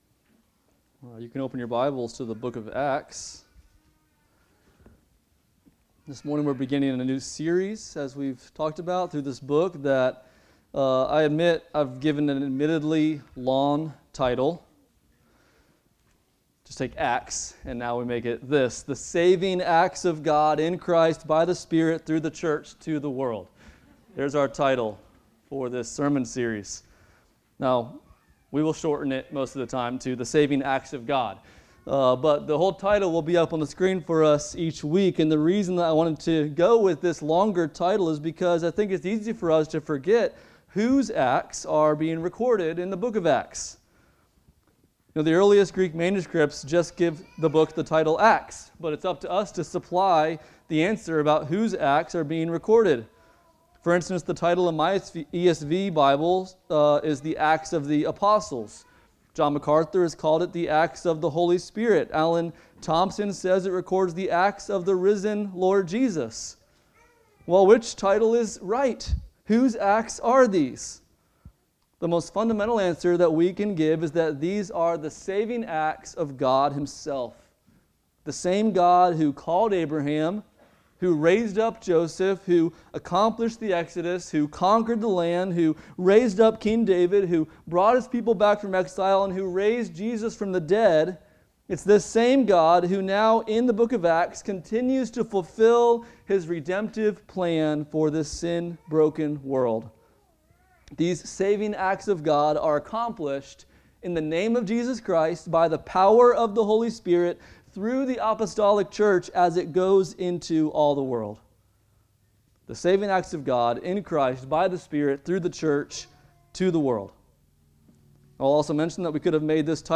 Passage: Acts 1:1-11 Service Type: Sunday Morning